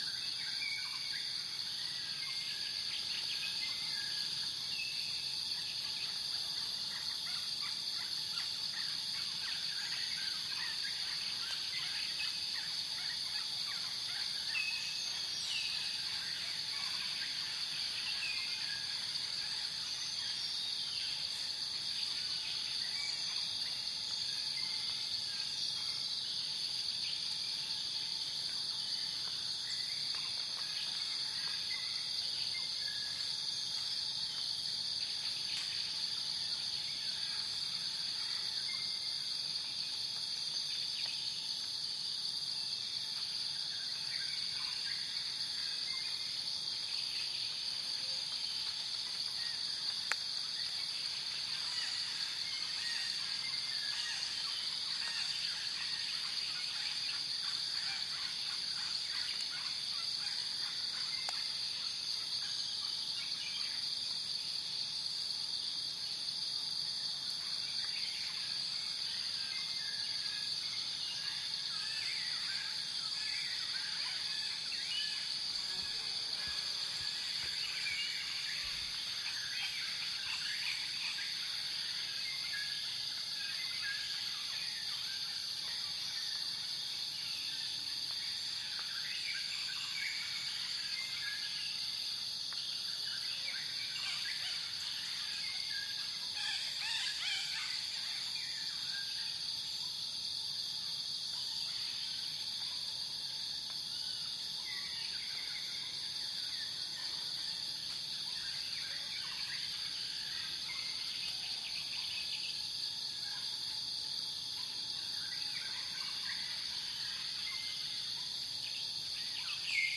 Тропический лес – Добавь экзотики